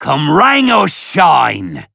Vo_dino_rang_catchphrase_01.ogg